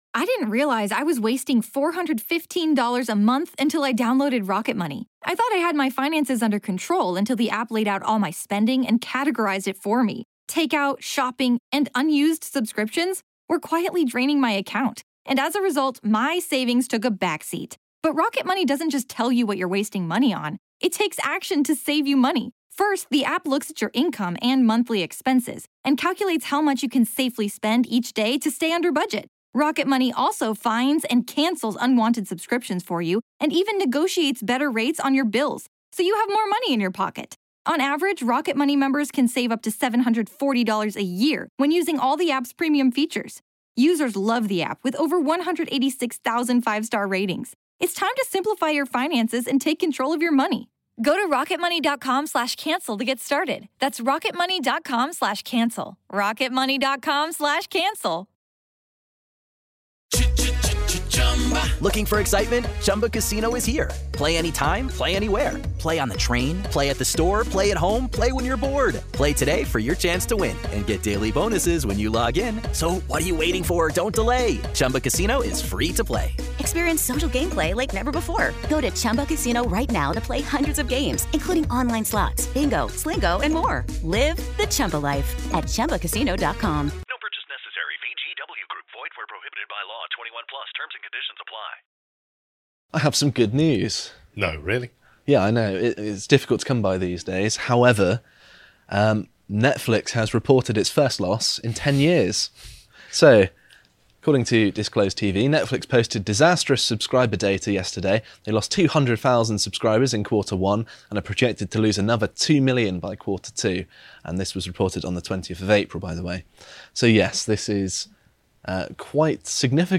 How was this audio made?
Pre-recorded Thursday 21/04/22.